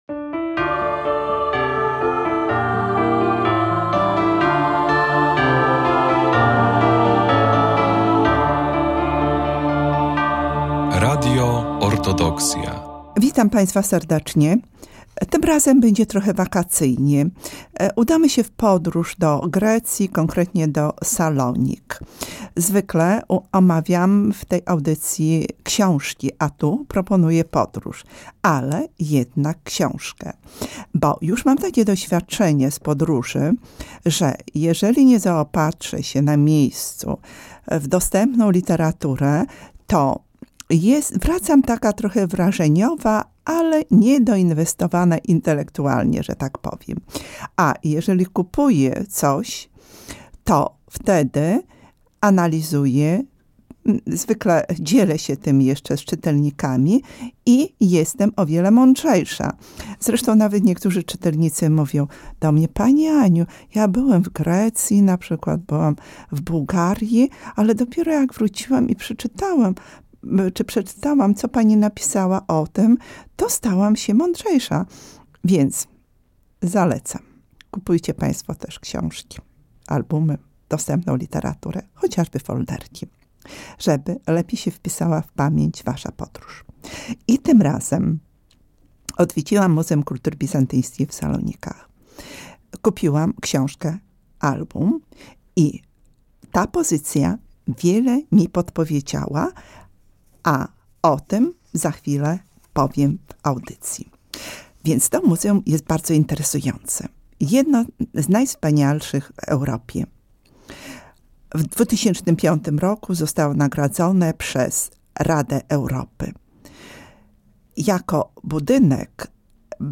to audycja o książkach w Radiu Orthodoxia